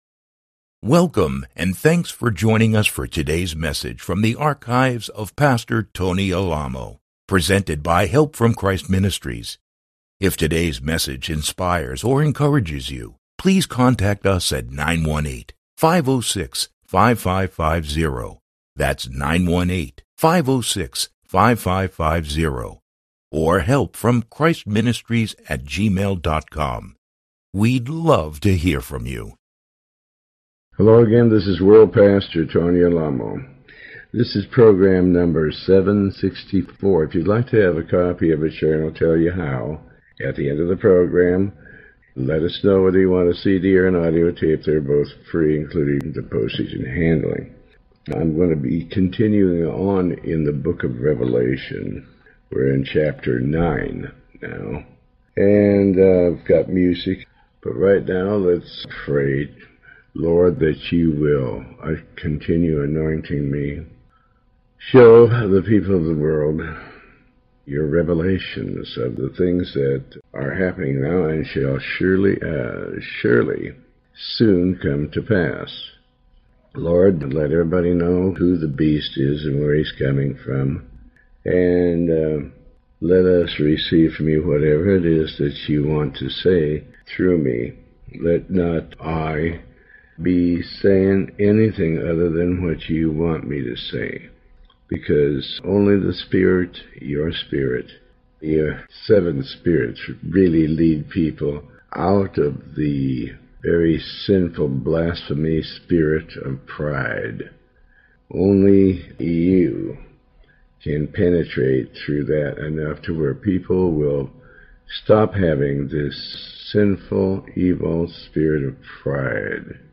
Pastor Alamo reads and comments on the Book of Revelation chapters 9. This program is part of a series covering the entire Book of Revelation